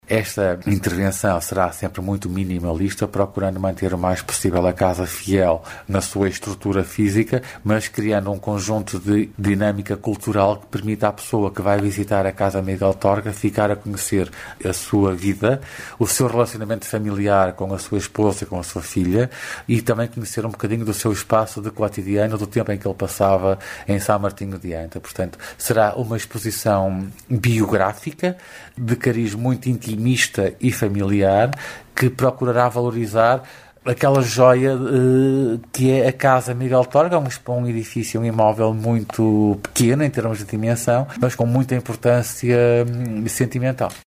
Numa altura em que se evocam 20 anos da morte do escritor, a Direção Regional da Cultura do Norte está a ultimar o projeto para transformar a antiga e pequena casa de Torga, num espaço de visita, como adianta o diretor regional de Cultura, António Ponte.